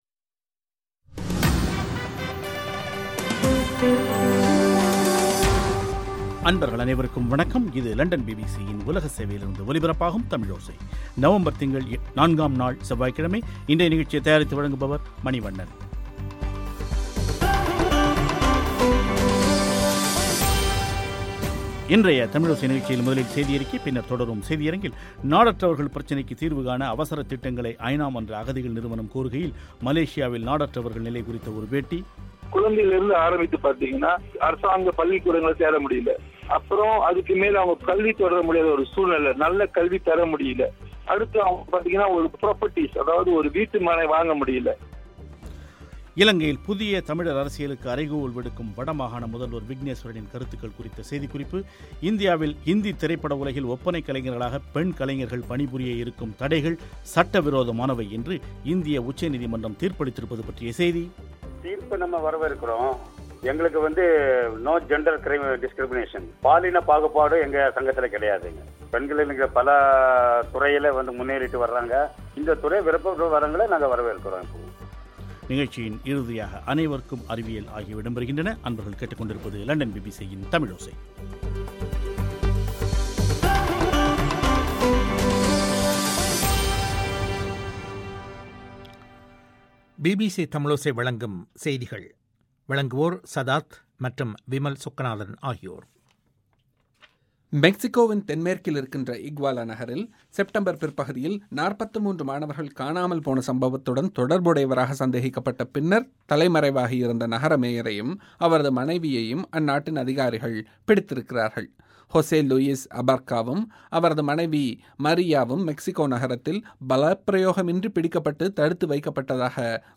நாடற்றவர்கள் பிரச்சனைக்கு தீர்வு காண அவசரத் திட்டங்களை ஐநா மன்ற அகதிகள் நிறுவனம் கோருகையில், மலேசியாவில் நாடற்றவர்கள் நிலை குறித்த ஒரு பேட்டி